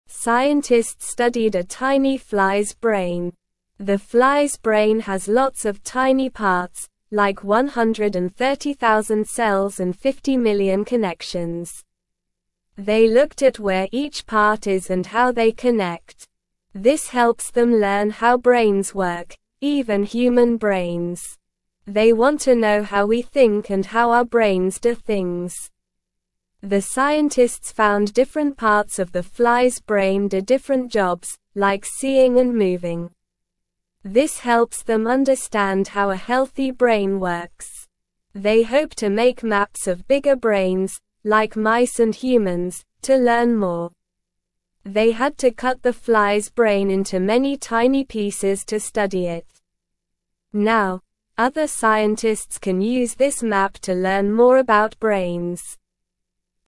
Slow
English-Newsroom-Beginner-SLOW-Reading-Scientists-study-tiny-fly-brain-to-learn-more.mp3